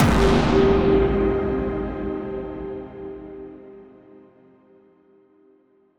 Synth Impact 06.wav